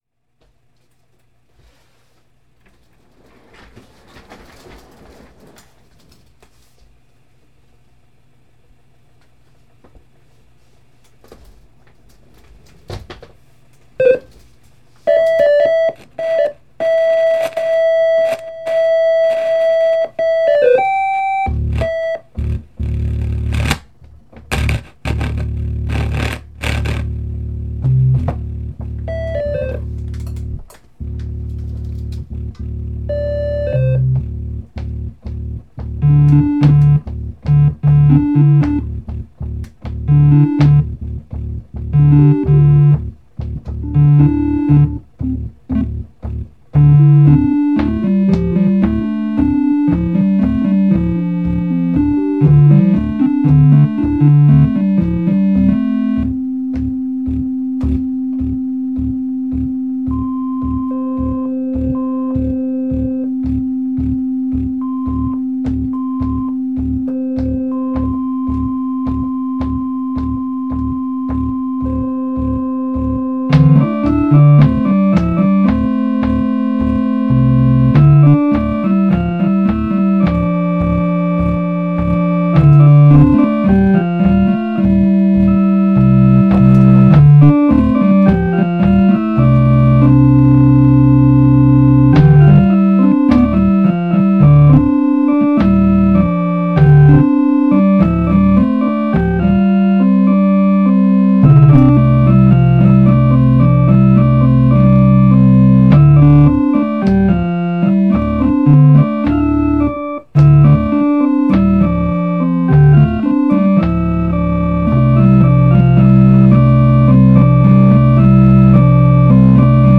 I went back to the fast impulsive method of just setting up a mic and pushing record. No time given to even wear headphones with a click.
FreetimeOrganAug21st.mp3